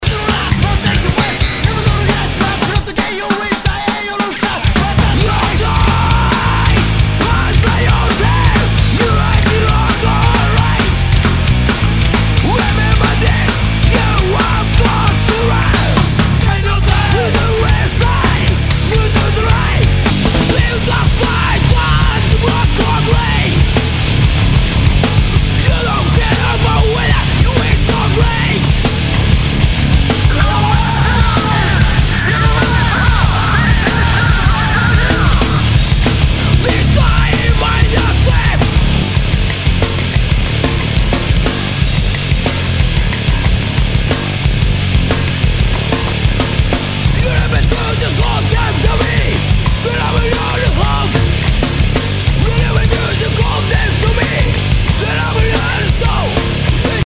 ROCK/POPS/INDIE
ナイス！ハードコア！